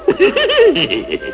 Here you will find tons of great sounds and other downloads from actual episodes of Scooby Doo. Choose from any of over 150 wav files, each of excellent sound quality.
Hehe.wav: Scooby's famous giggle.